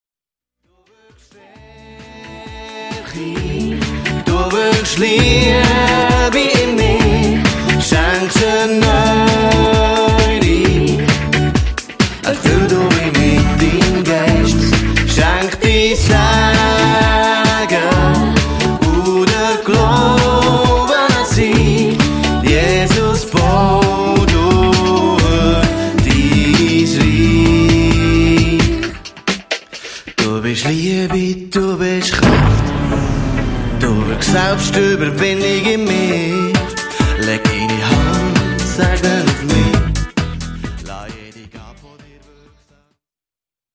Songs (Lead Vocals)